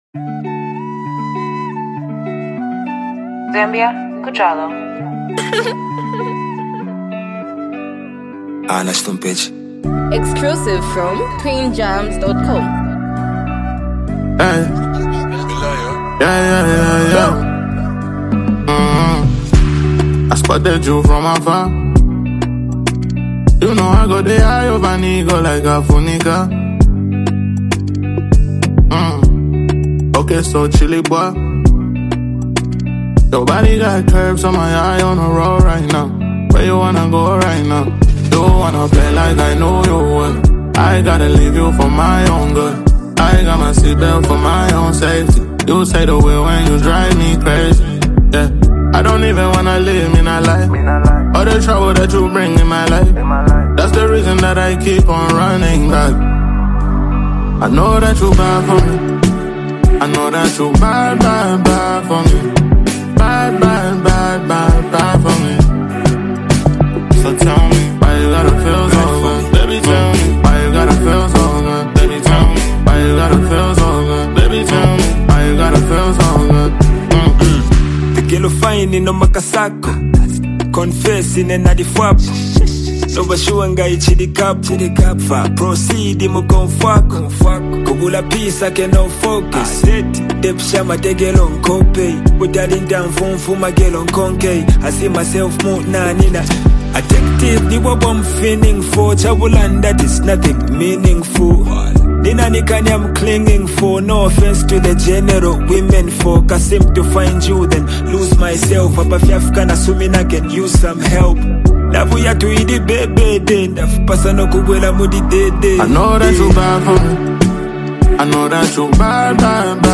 The song carries a laid-back but triumphant mood